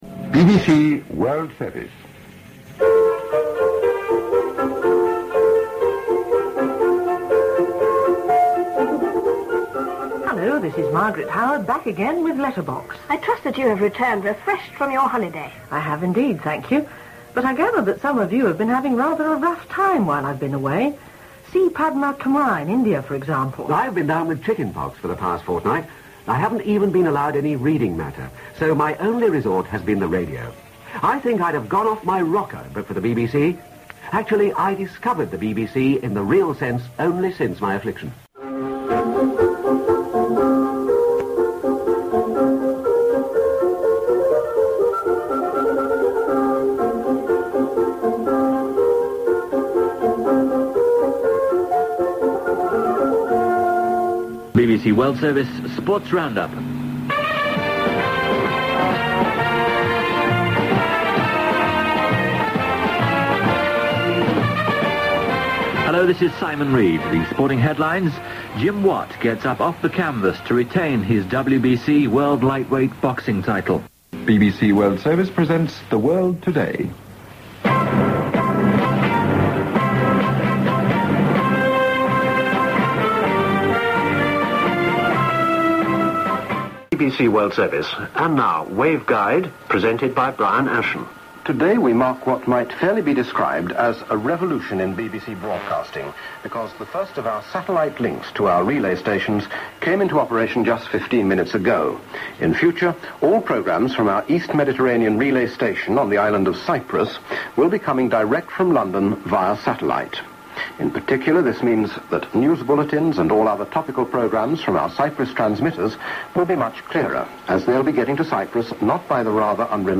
A montage of BBC World Service programmes (2 of 3) recorded in about 1980.